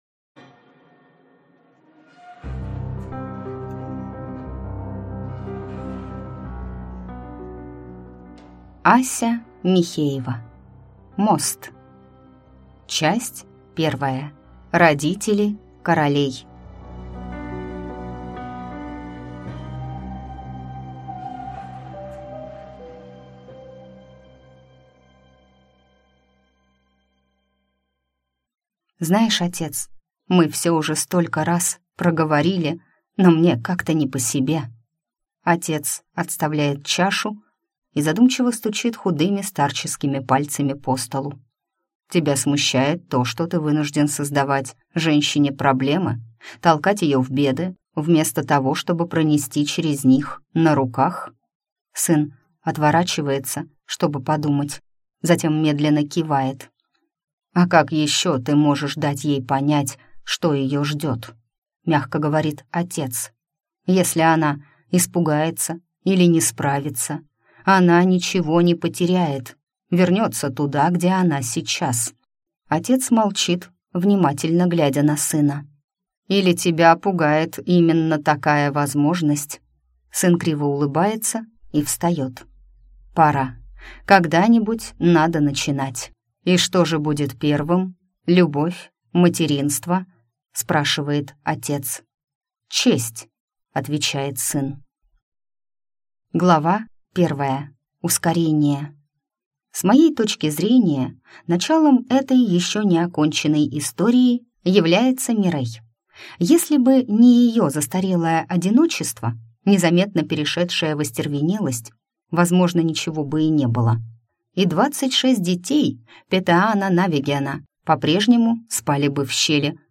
Аудиокнига Мост | Библиотека аудиокниг